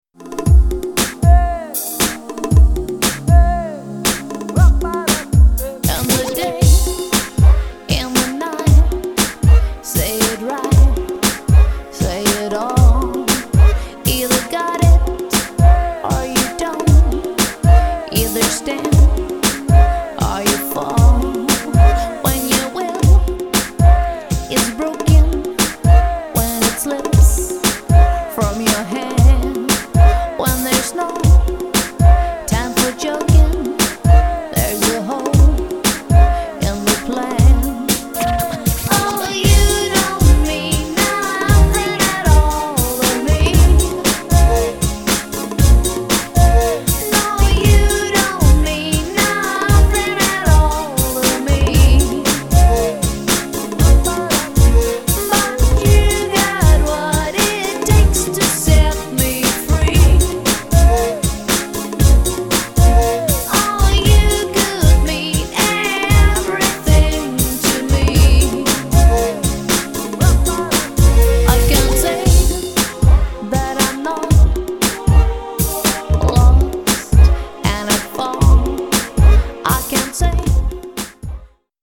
- das DUO klingt bereits wie eine ganze 5-Mann-Band
- ECHTE Live-Musik & Live-Gesang mit 2 SUPER Solostimmen
• Coverband